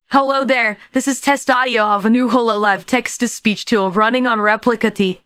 audio-to-audio speech-style-transfer text-to-speech
🎙Hololive text-to-speech and voice-to-voice (Japanese🇯🇵 + English🇬🇧)
"style": "Neutral",
"speaker": "EN_MoriCalliope",